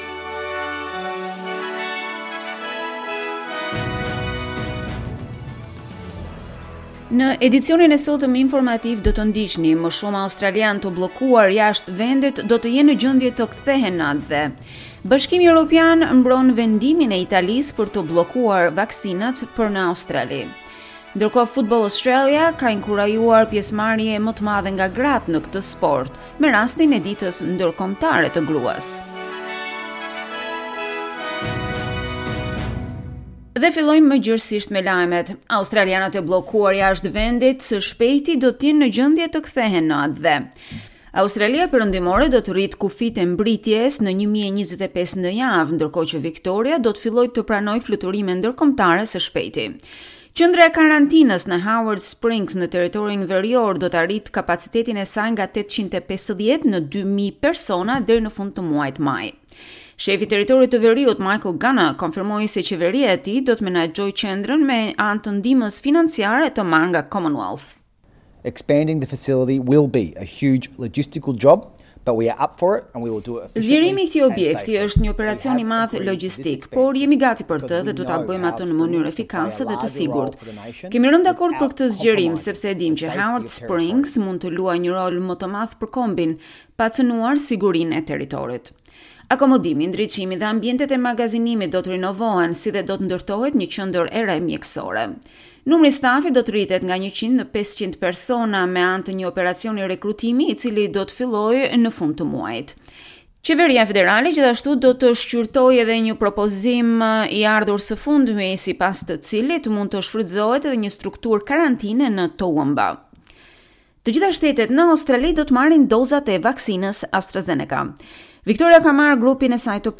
SBS News Bulletin in Albanian - 06 March 2021